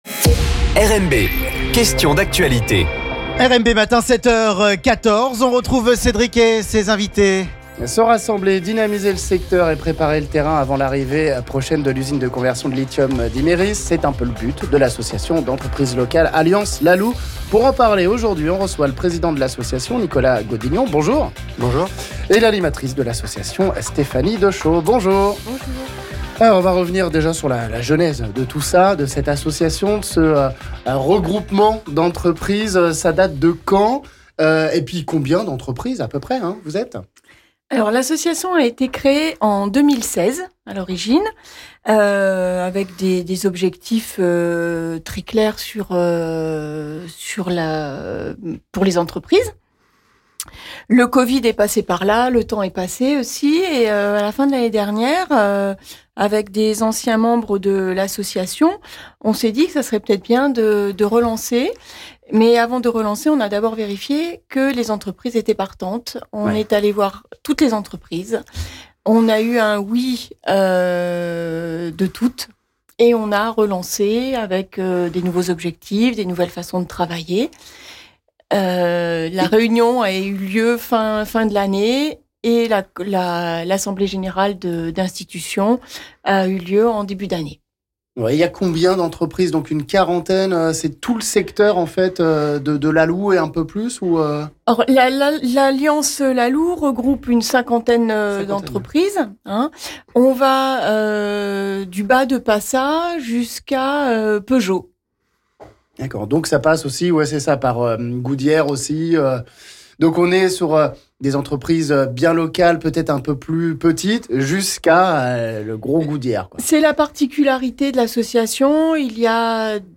Ils veulent embellir et sécuriser le secteur de la Loue, le dynamiser et renforcer l'attractivité...les membres d'association Alliance la Loue, qui regroupe 50 entreprises du secteur dont Goodyear ou encore Pokee Sport Publicité, étaient nos invités ce mercredi sur RMB.